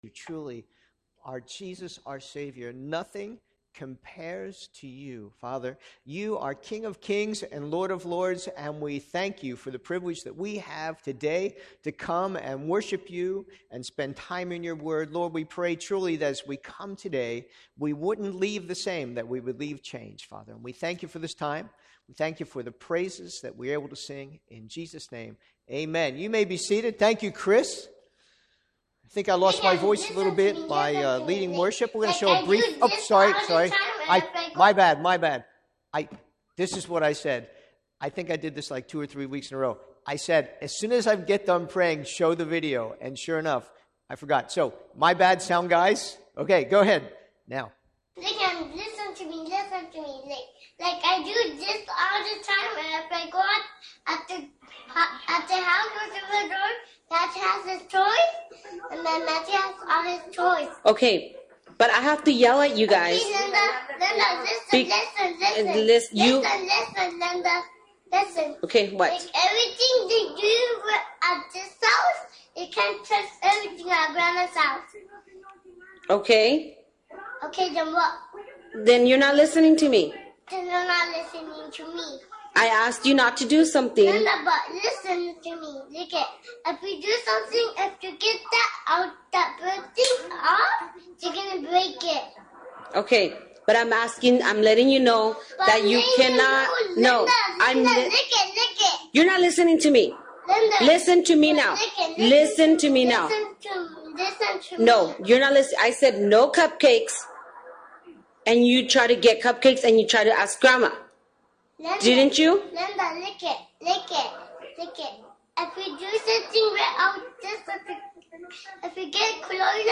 A message from the series "English Sermons."